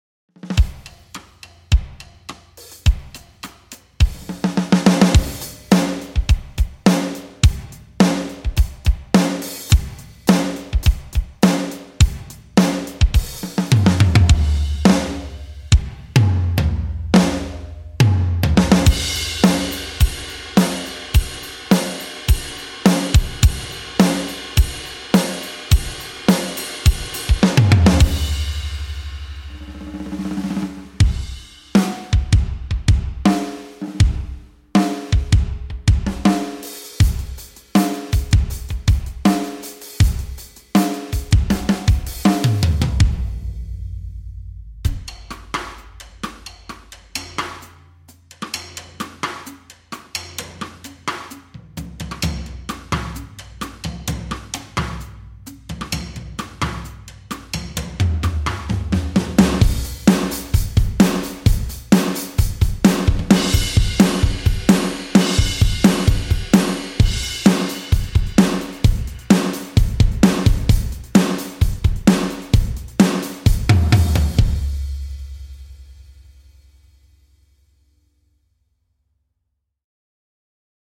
drum sample library
drum samples